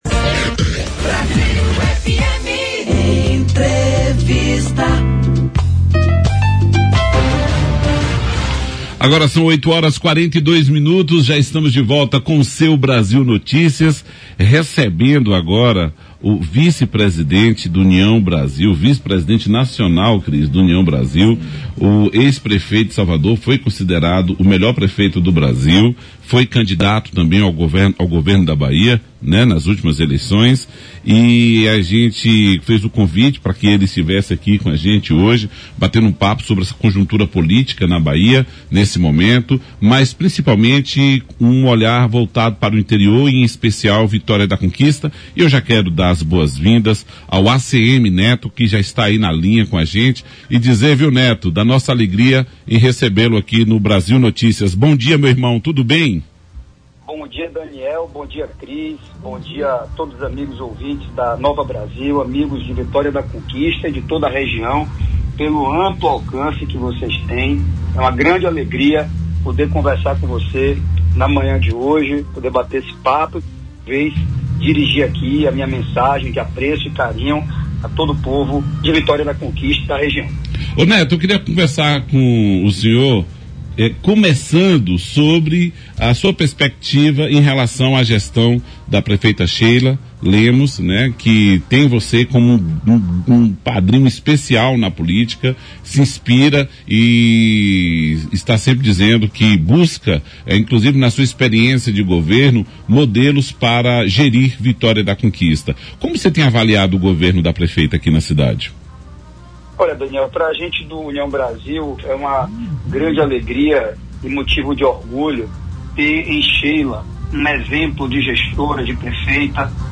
Entrevista ao Brasil Notícias | ACM Neto critica gestão de Jerônimo Rodrigues e aponta desafios da Bahia
Fotos: BLOG DO ANDERSON Ainda na entrevista ao Brasil Notícias, programa da Rádio Brasil, emissora de Vitória da Conquista, na manhã desta terça-feira (25), o vice-presidente do União Brasil, Antônio Carlos Peixoto de Magalhães Neto, o ACM Neto, aproveitou para criticar a gestão do seu principal adversário, o governador da Bahia, Jerônimo Rodrigues Souza, do Partido dos Trabalhadores. O ex-prefeito de Salvador apontou falhas na segurança, educação, saúde e economia do estado, além de questionar a continuidade do PT no governo.